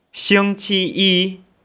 (Click on any Chinese character to hear it pronounced.